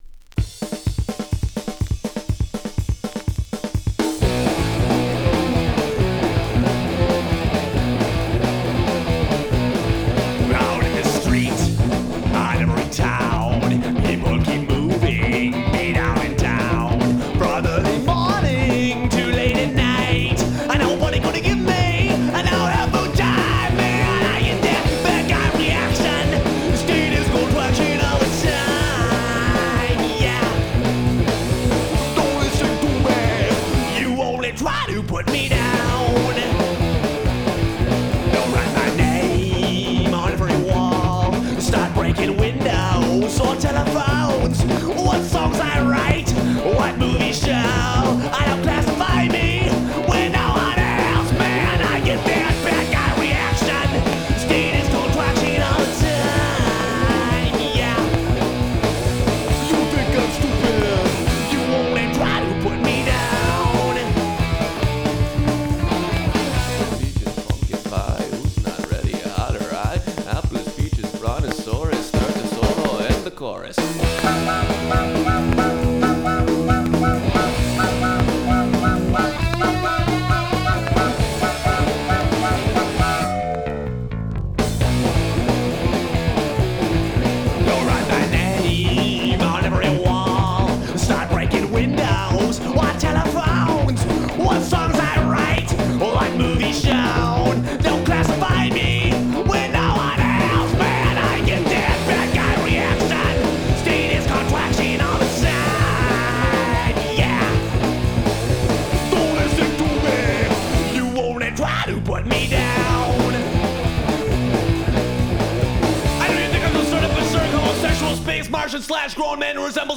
Recorded at Simple Studios, Green Bay, WI.
Guitar
Bass
Drums
Vocals
Pop-Punk